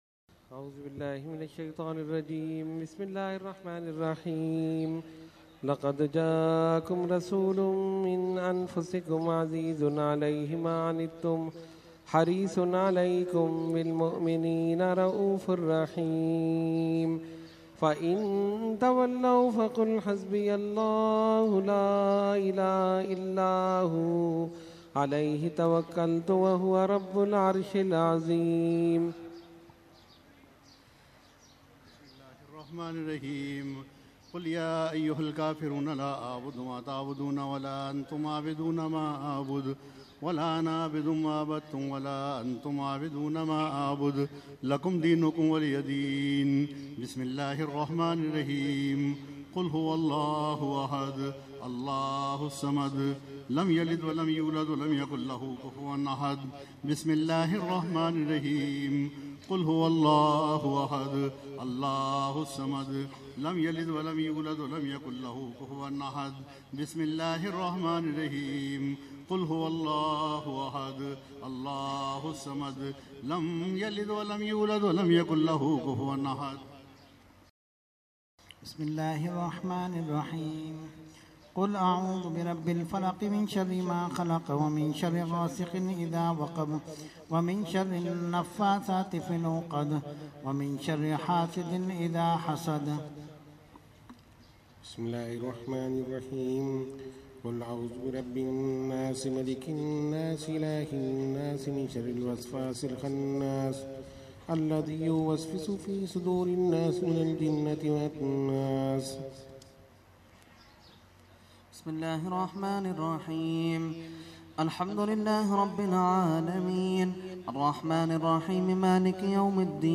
Fatiha – Urs Qutbe Rabbani 2016 Day 3 – Dargah Alia Ashrafia Karachi Pakistan